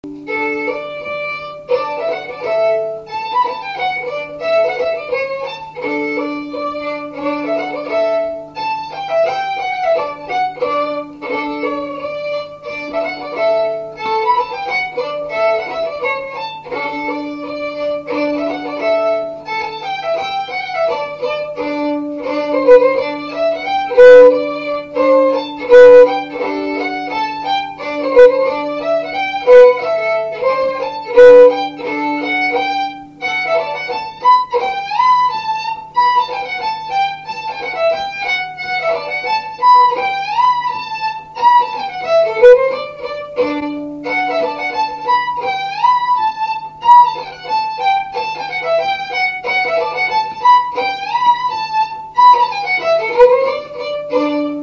Scottish
Mouchamps ( Plus d'informations sur Wikipedia ) Vendée
Résumé instrumental
danse : scottish